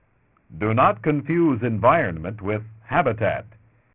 BTW attached is a 6000Hz 4-bit version, (which is 3.0Kb/s, a bit over what you specified: 2.85Kb/second ], I don’t think you can’t afford 8-bit depth and meet your specifications, 4-bit or even lower will be necessary.